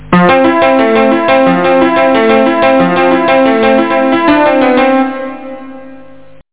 PianoMelody3.mp3